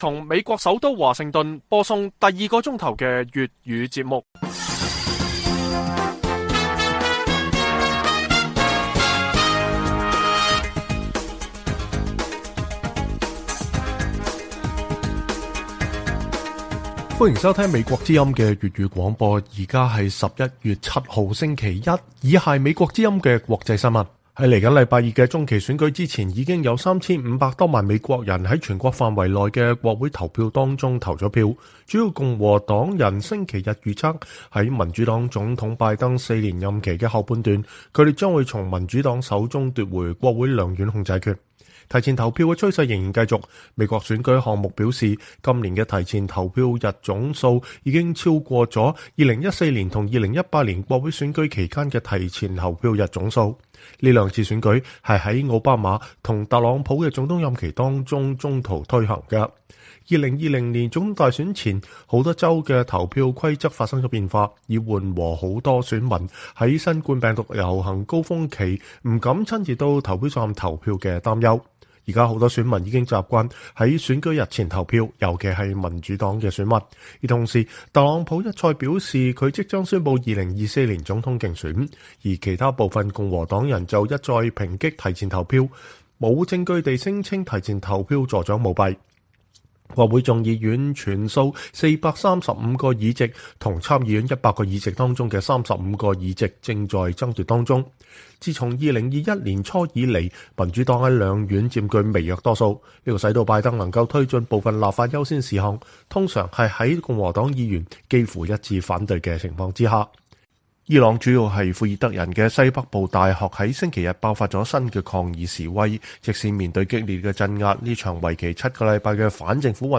粵語新聞 晚上10-11點: 提前投票在美國增加，共和黨預測會奪回國會兩院